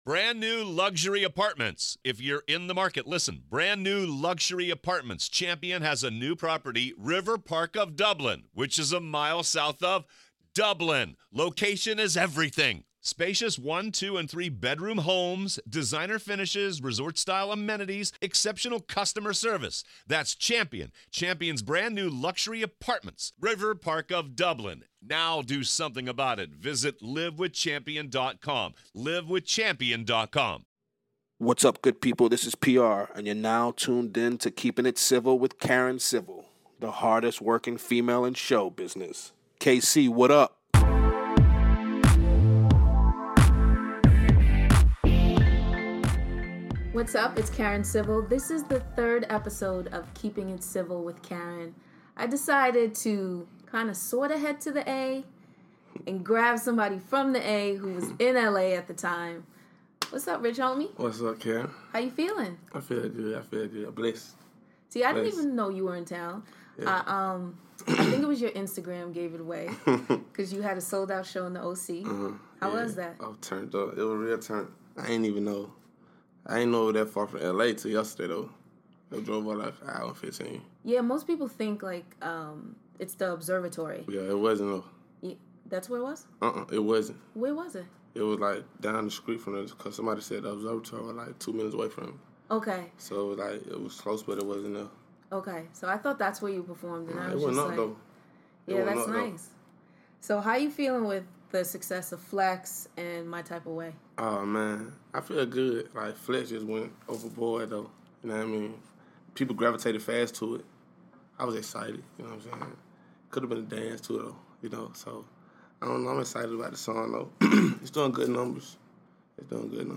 Episode 03: Rich Homie Quan Interview